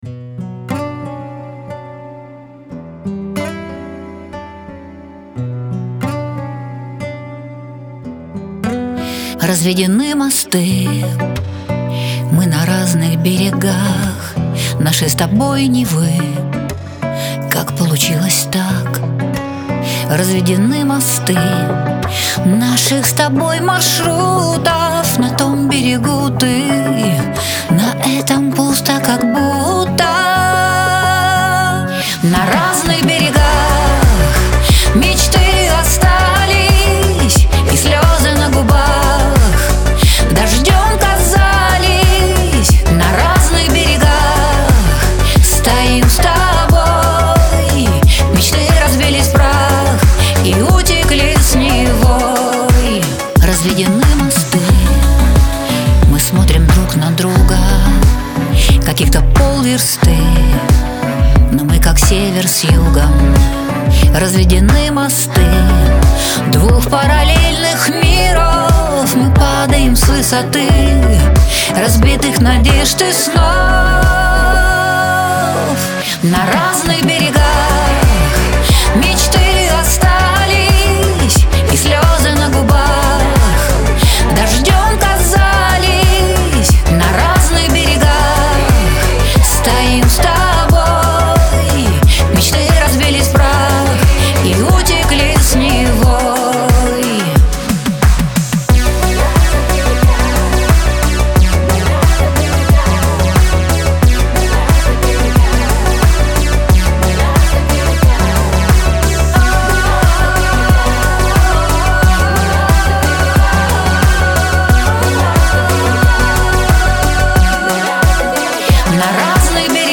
эстрада
грусть , pop